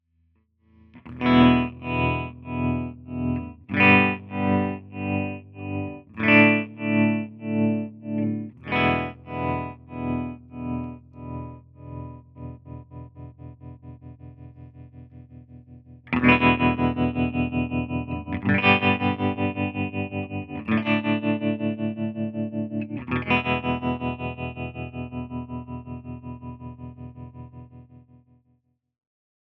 My latest guitar amp project is a stereo amp with vibrato and reverb.
tremolomono1_r1_session.flac